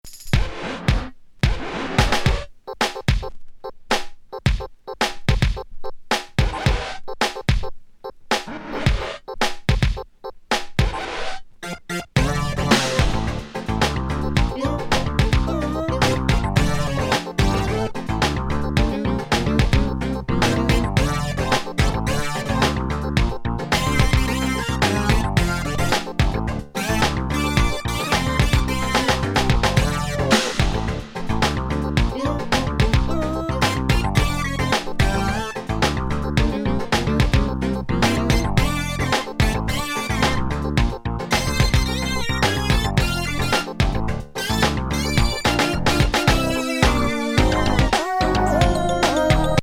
ズッコケ・コミカル・チープ・エレクトロ・グルーブ